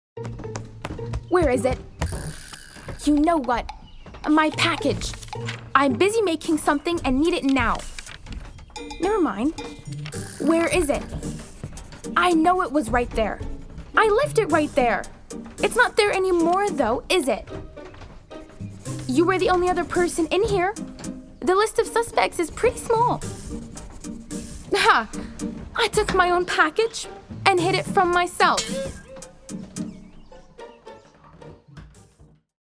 Animation (2) - ANG